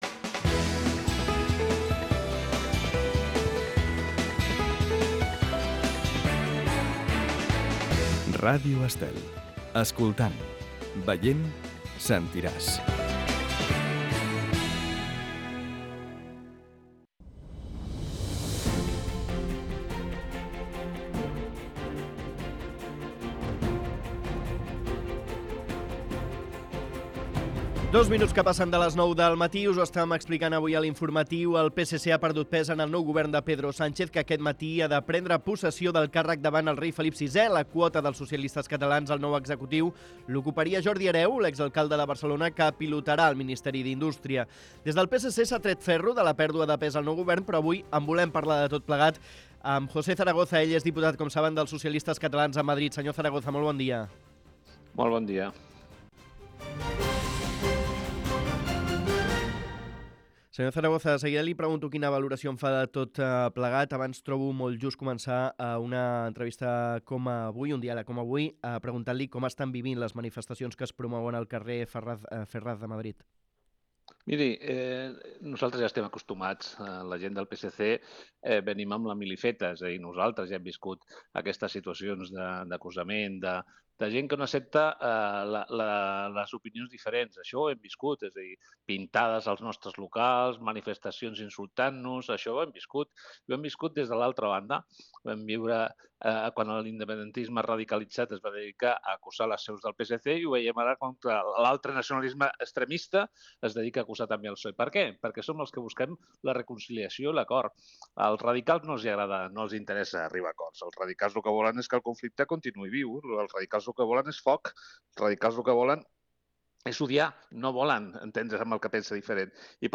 José Zaragoza ha criticat el govern d'Aragonès en una entrevista a La Caravana de Ràdio Estel.